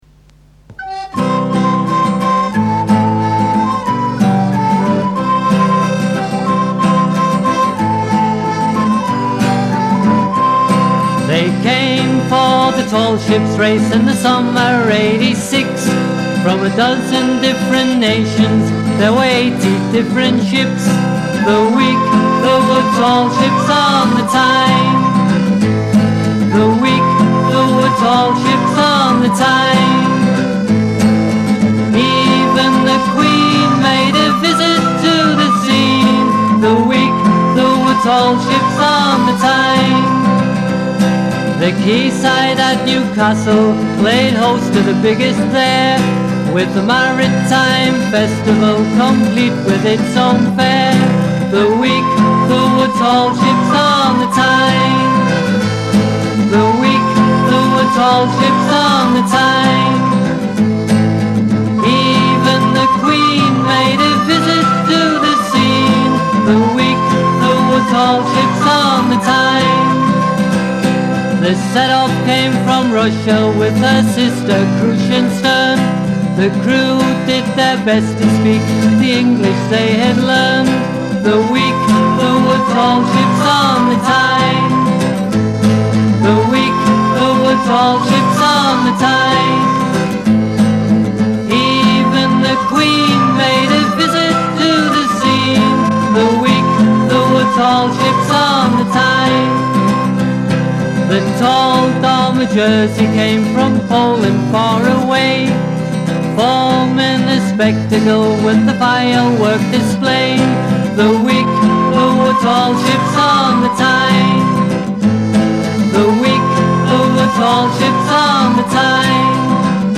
Vocals & Guitar
Recorder
Flute
Piano Accordion.
folk style songs